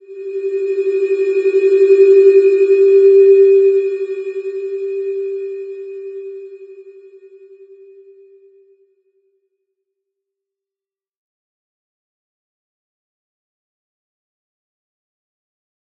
Slow-Distant-Chime-G4-f.wav